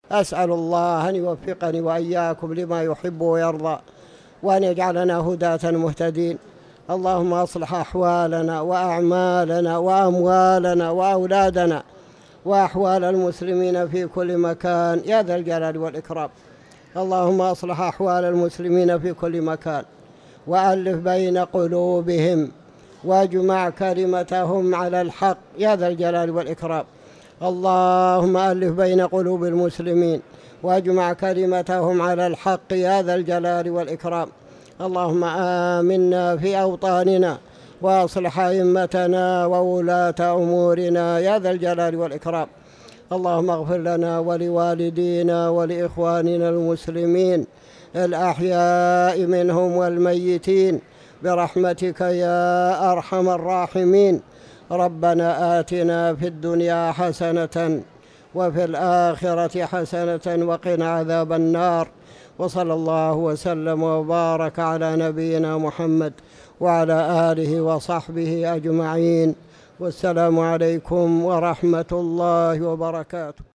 تاريخ النشر ٦ جمادى الآخرة ١٤٤٠ هـ المكان: المسجد الحرام الشيخ